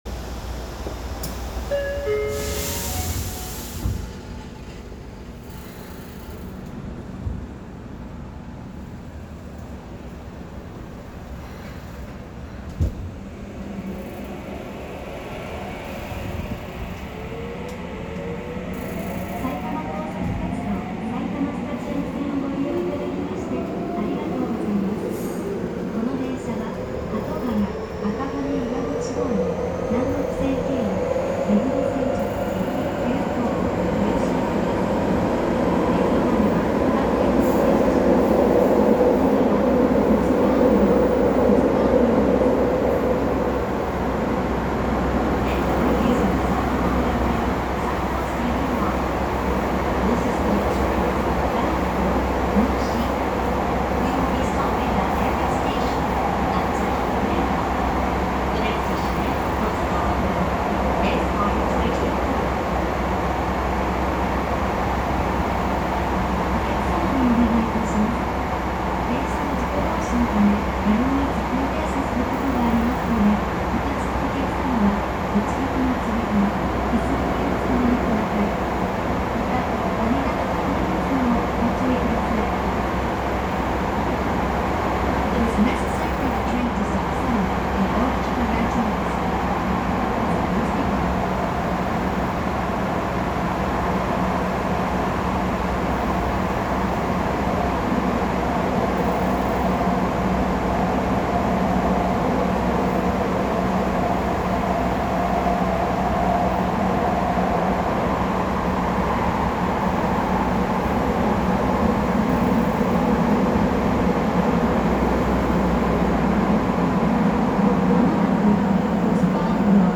・2000系走行音
【埼玉高速線】東川口→戸塚安行
基本的に走行音は東京メトロ05系の8次車と同様で、三菱のIGBT。